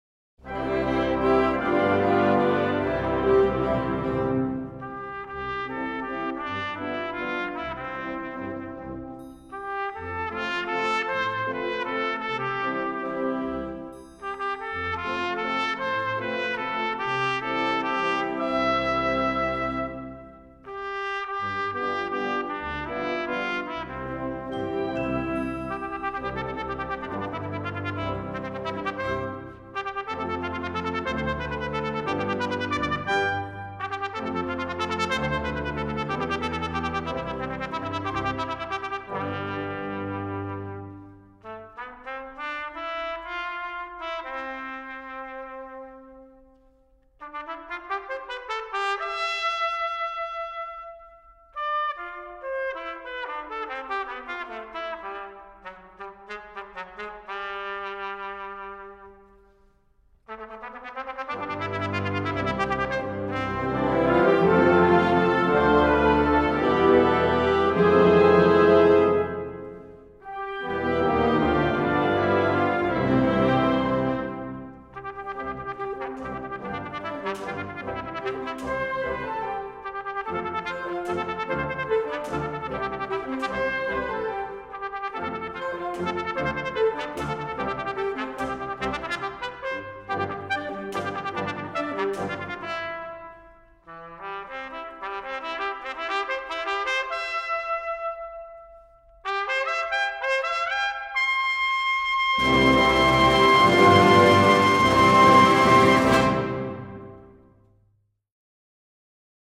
Concert March